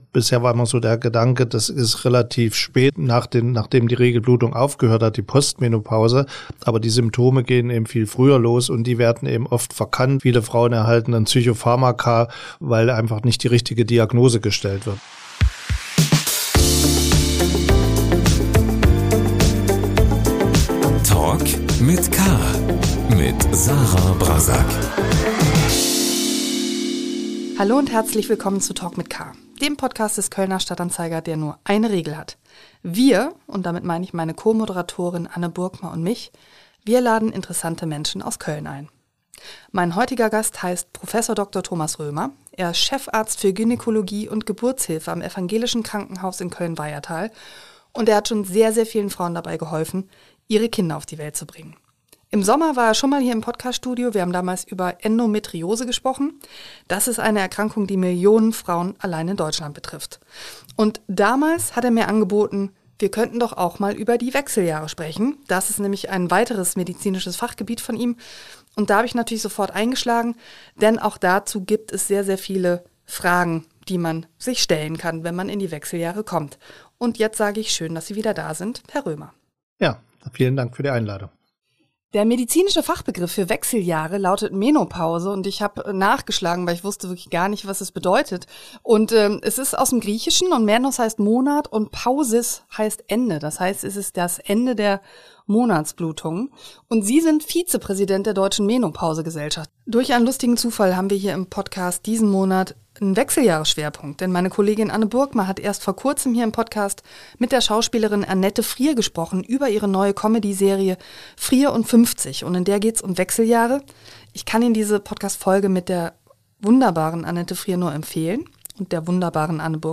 Der Kölner Gynäkologe im Talk ~ Talk mit K - der Talk-Podcast des Kölner Stadt-Anzeiger Podcast